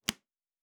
pgs/Assets/Audio/Fantasy Interface Sounds/Cards Place 03.wav
Cards Place 03.wav